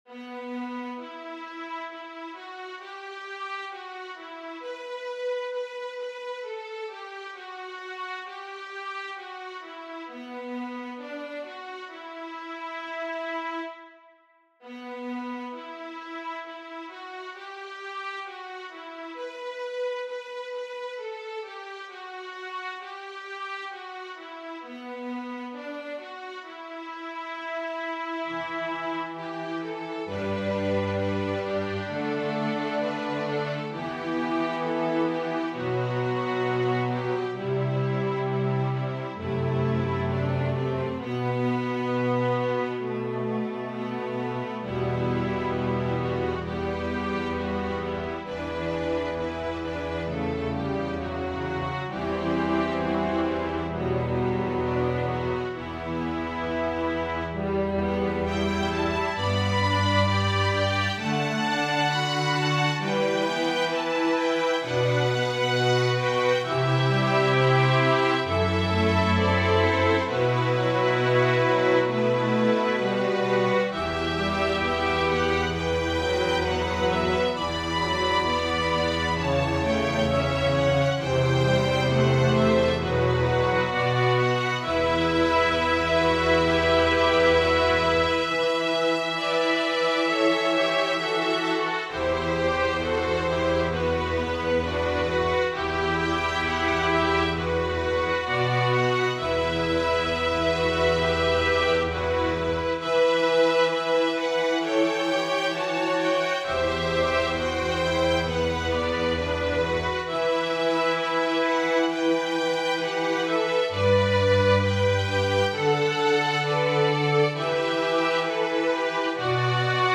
Organ solo
Voicing/Instrumentation: Organ/Organ Accompaniment